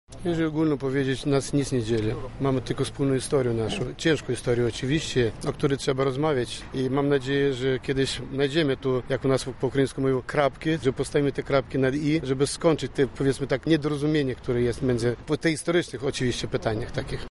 Odsłonięcie pomnika